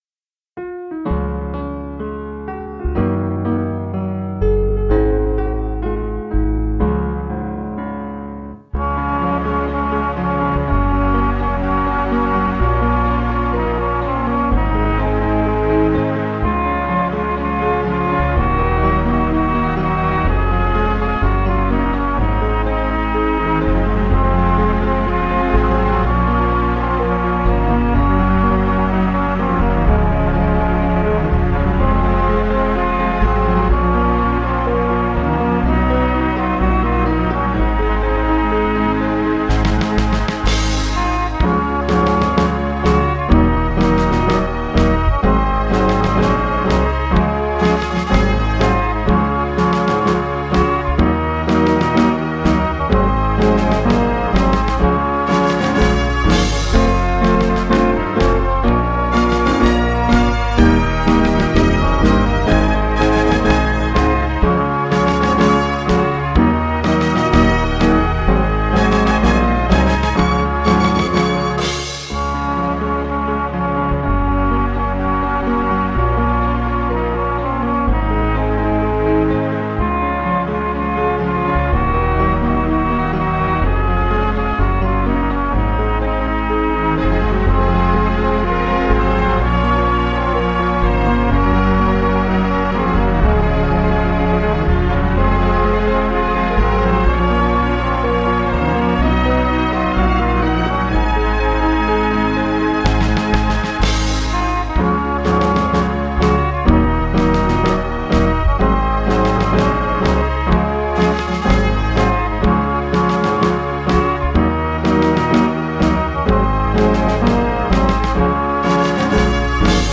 Hymn Szkoły melodia [3.13 MB]